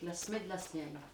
Collectif - ambiance
Catégorie Locution